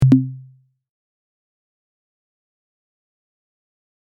Buttons and Beeps
button 1.mp3